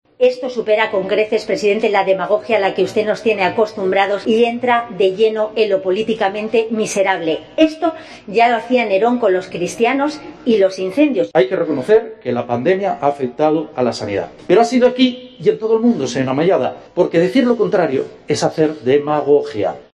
Duro cruce de acusaciones en el parlamento asturiano entre la presidenta del PP y el presidente del Principado